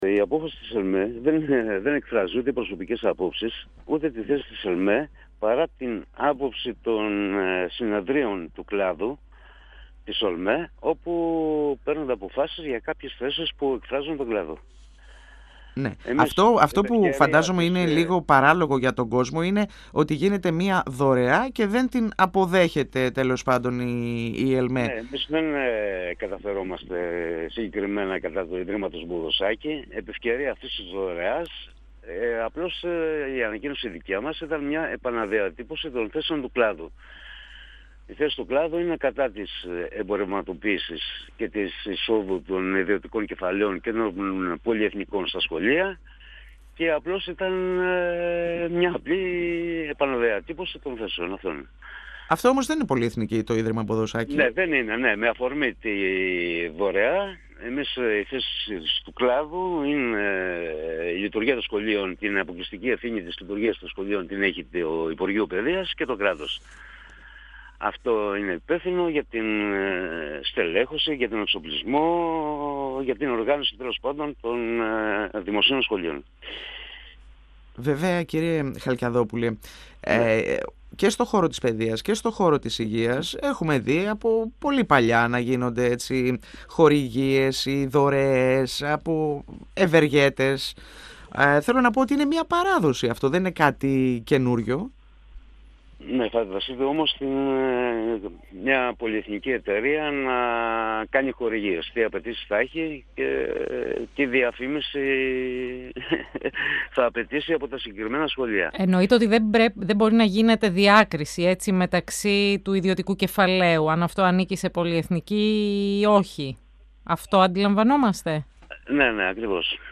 Κατηγορηματικά αντίθετη σε οποιαδήποτε προσπάθεια εμπορευματοποίησης της Παιδείας δηλώνει η ΕΛΜΕ Μαγνησίας με αφορμή χορηγία του Ιδρύματος Μποδοσάκη για τον εξοπλισμό εργαστηρίων σε γυμνάσια της περιοχής. Για το θέμα μίλησε στον 102 fm της ΕΡΤ3